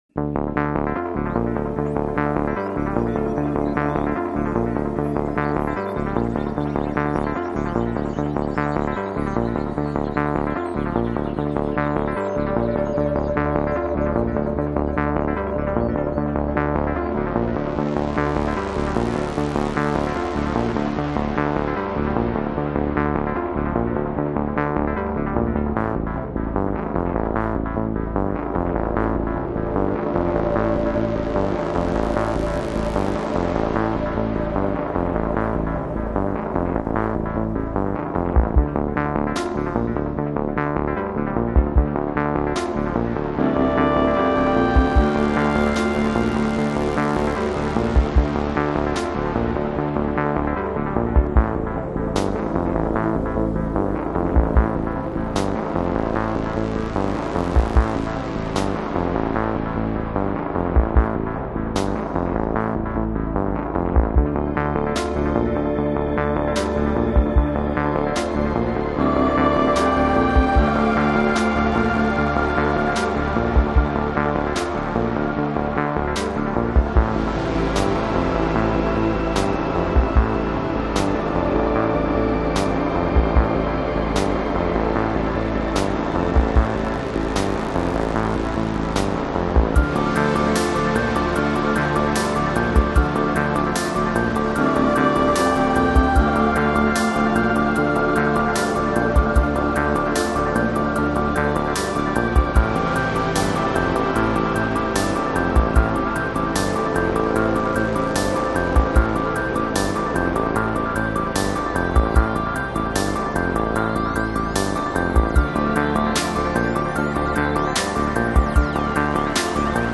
Downtempo | Chillout | Ambient Rock